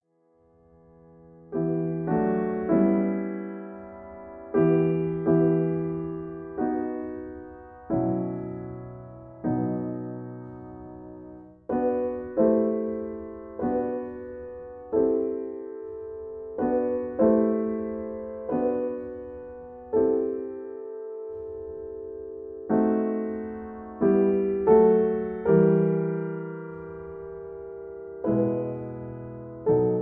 In E minor.